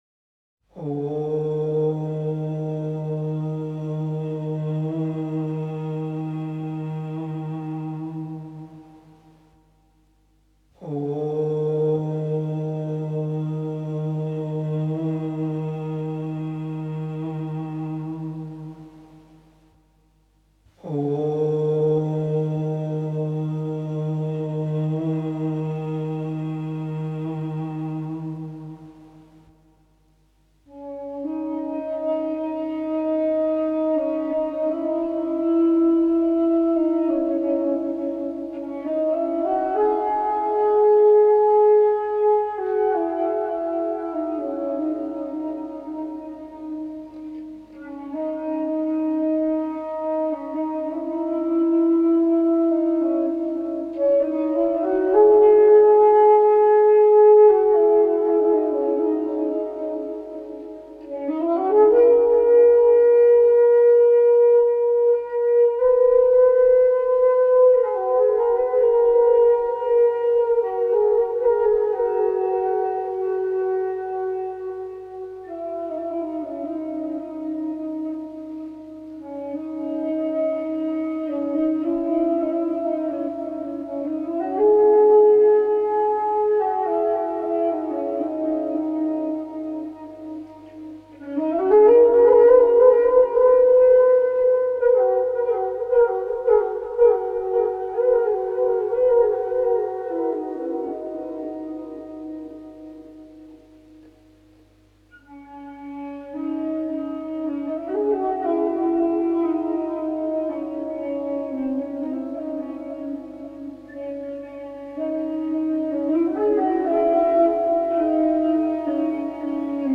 solo flute compositions created in a high meditative state.